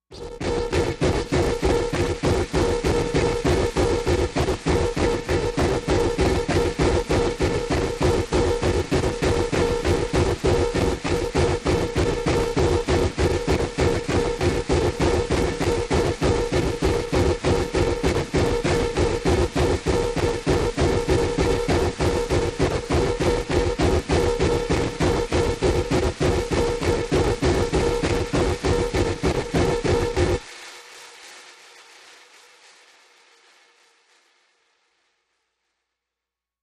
Scandal Impact, Machine, Electric Banging, Hollow White Noise, Arc